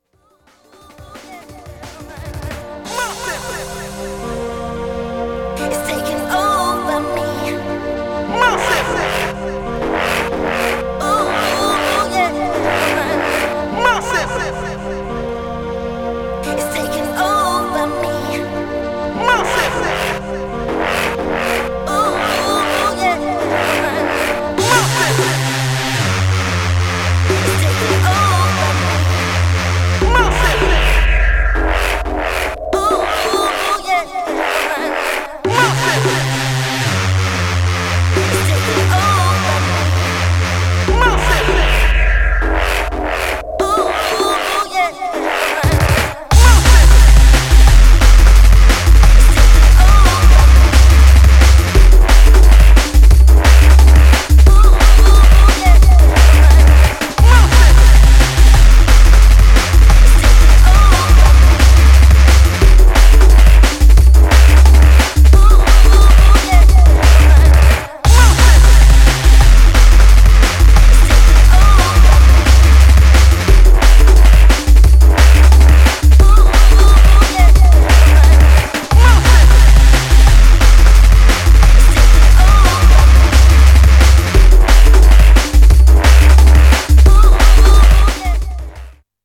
Styl: Breaks/Breakbeat Vyd�no